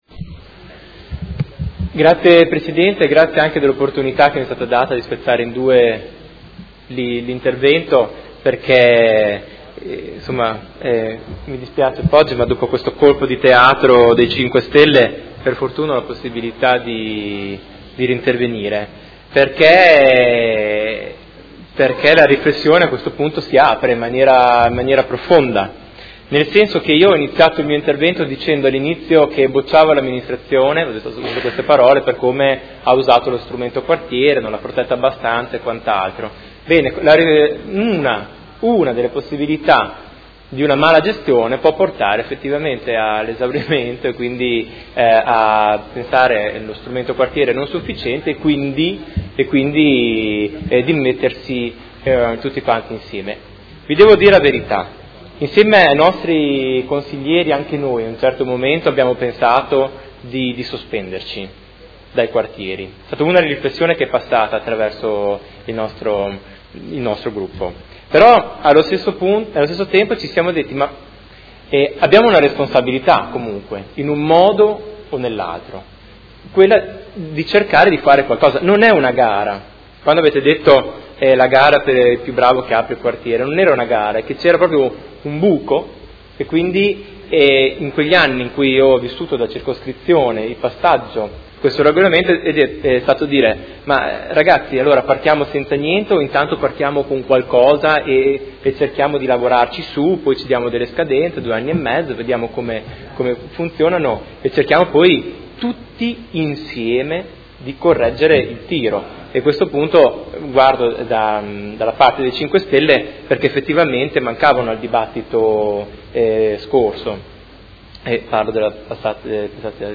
Seduta del 30/05/2016. Situazione dei Quartieri a Modena – dibattito